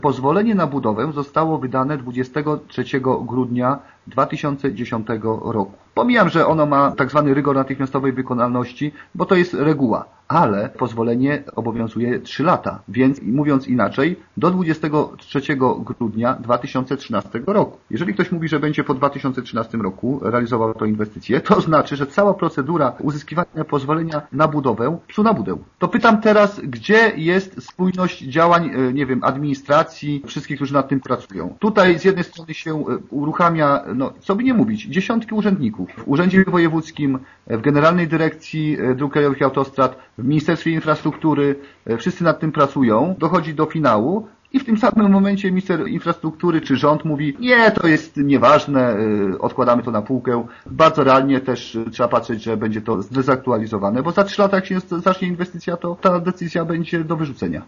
Wojciech Żukowski zwraca też uwagę, że przesunięcie inwestycji poza rok 2013 będzie miało bardzo poważne konsekwencje proceduralne, które mogą spowodować kolejne opóźnienia: